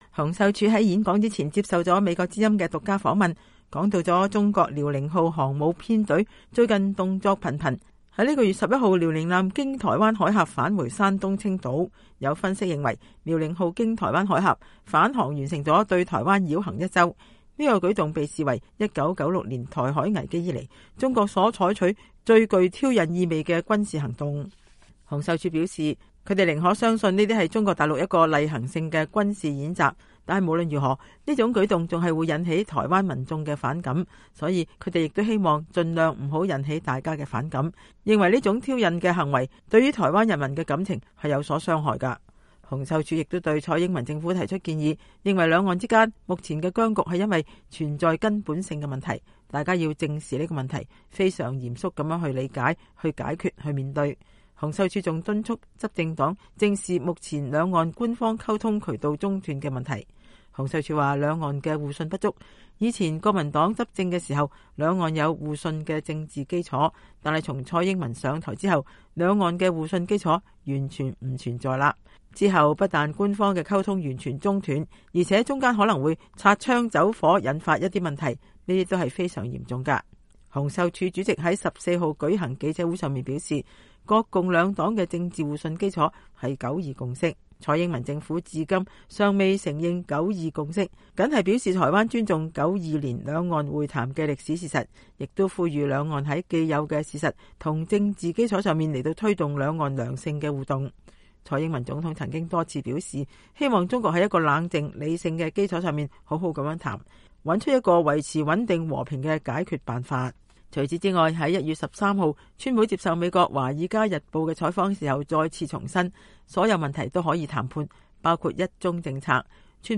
洪秀柱在演說前接受了美國之音獨家訪問，談到中國遼寧號航母編隊最近動作頻頻，本月11日遼寧艦經台灣海峽返回山東青島。